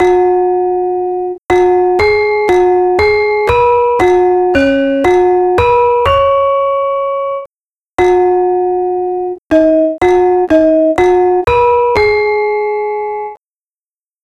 Beginning of the balungan for Ladrang Siyem sequenced with samples from Kyahi Paridjata of the gamelan group Marsudi Raras of Delft, transposed up 3 semitones